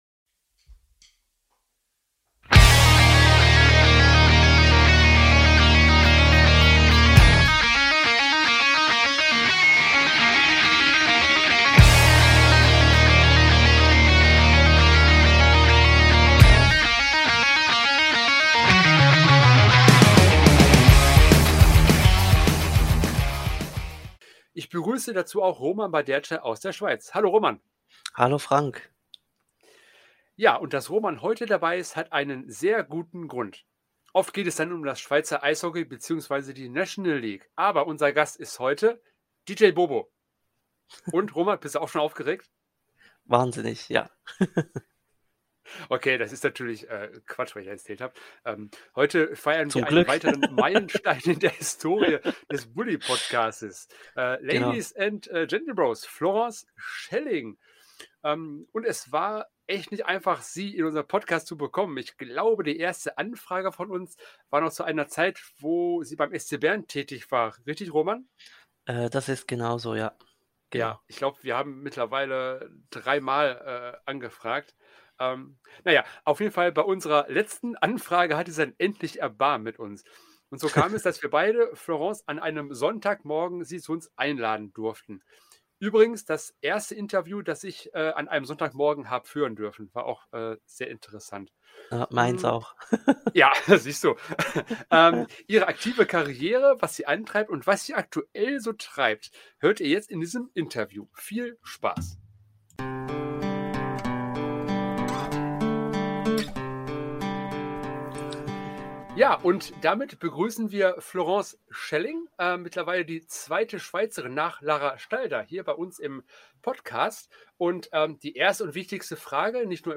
Bully #053 Florence Schelling im Interview